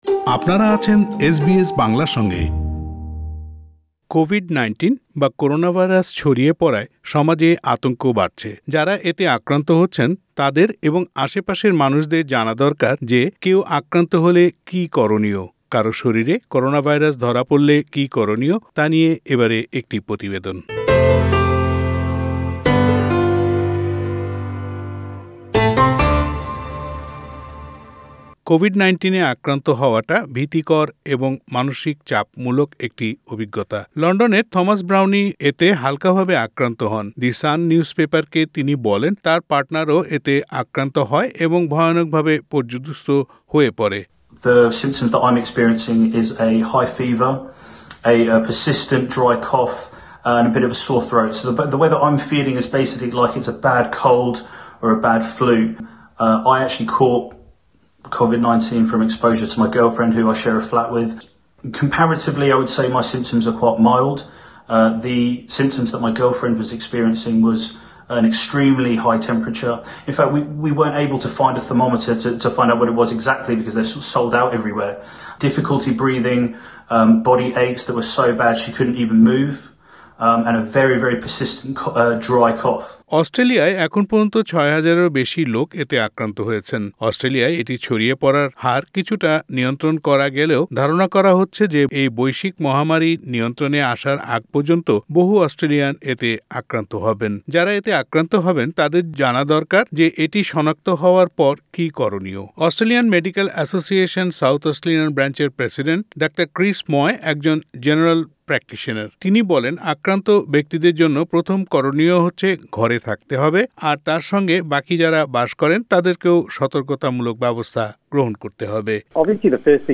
যারা এতে আক্রান্ত হচ্ছেন তাদের এবং আশেপাশের মানুষদের জানা দরকার যে, কেউ আক্রান্ত হলে কী করণীয়। কারও শরীরে করোনাভাইরাস ধরা পড়লে কী করণীয় তা নিয়ে একটি প্রতিবেদন।